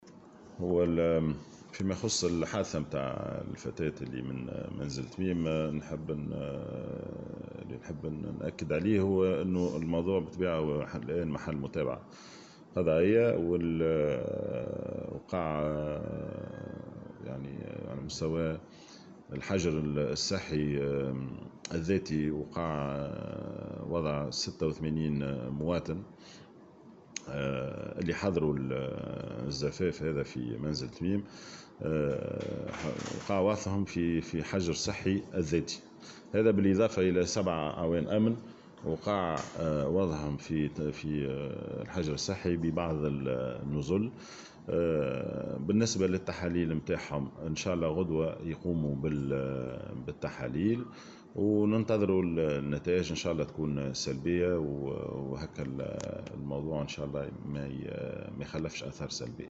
وأضاف في تصريح اليوم لمراسلة "الجوهرة أف أم" أنه تم إخضاع 86 شخصا حضروا حفل الزفاف بمنزل تميم (بما فيهم العروس) و7 أعوان أمن في الحجر الصحي الذاتي، في انتظار القيام بالتحاليل اللازمة يوم غد.